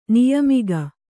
♪ niyamiga